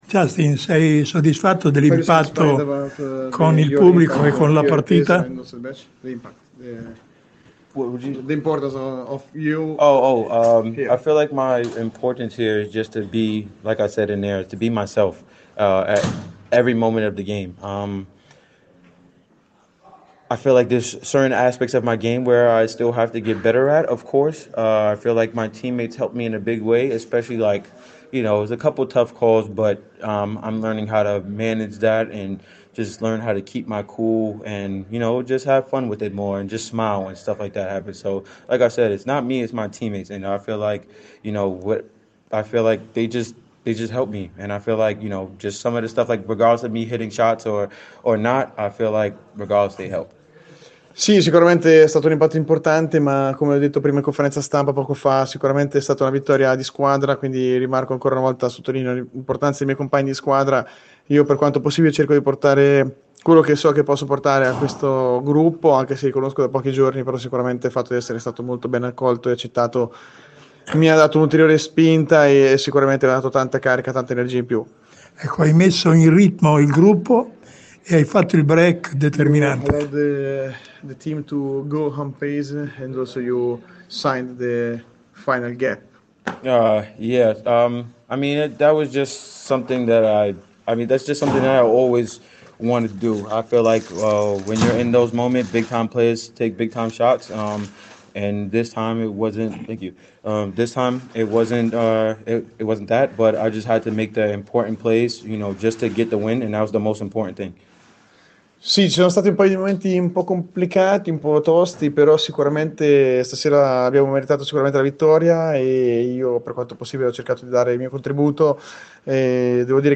Intervista nel dopo partita